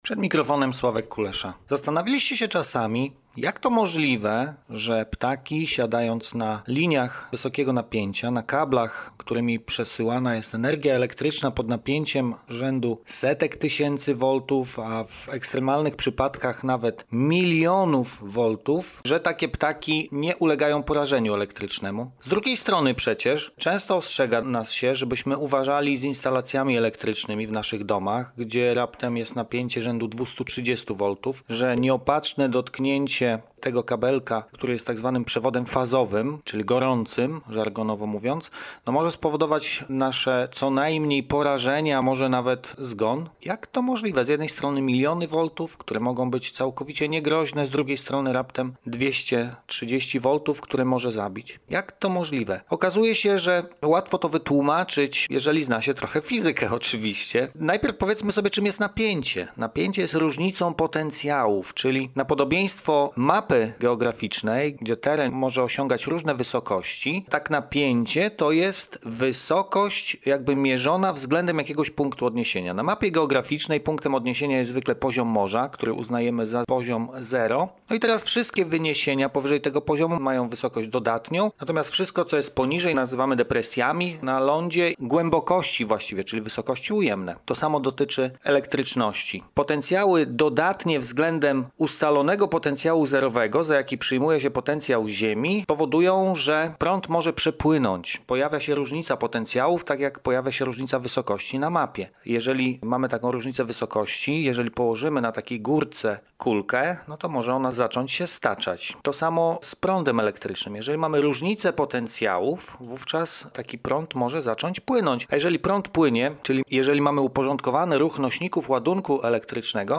Postój z Nauką robimy sobie na antenie Radia UWM FM od poniedziałku do czwartku około 14:15 w audycji Podwójne Espresso.